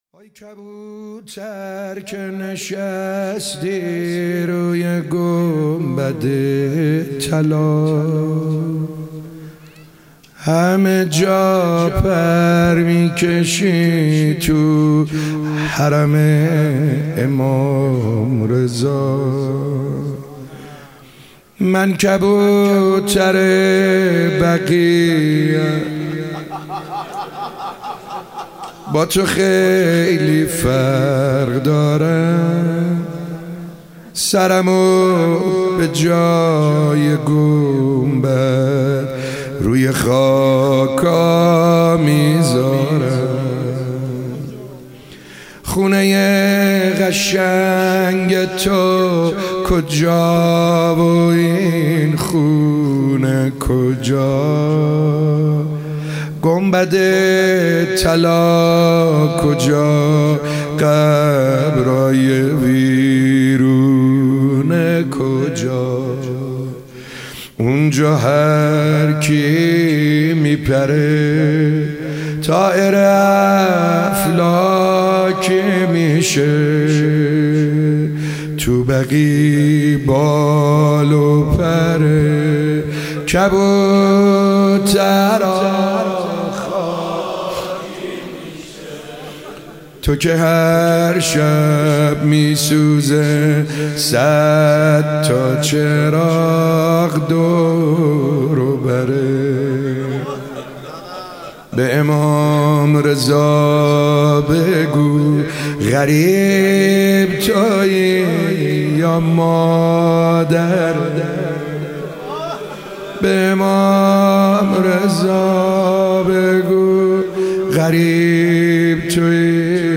شب 16 رمضان 97- روضه - وای کبوتر که نشستی روی گنبد طلا